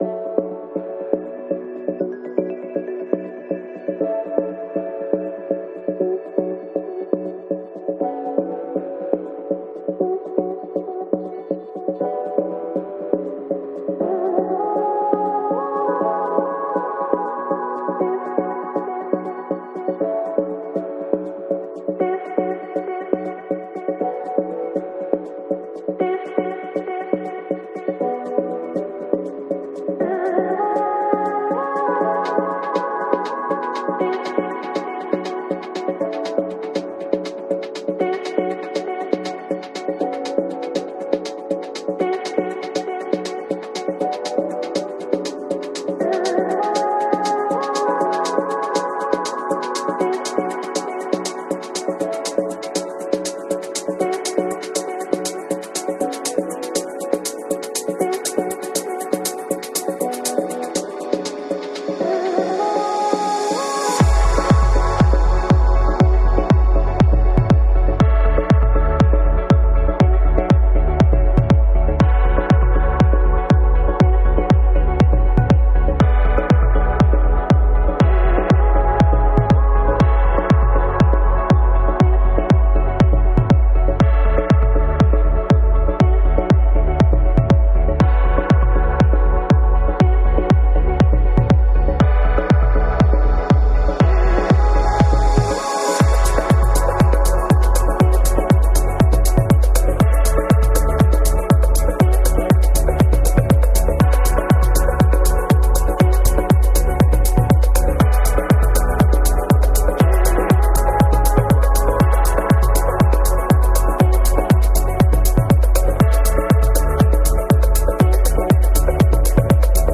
EP
Genre: Progressive House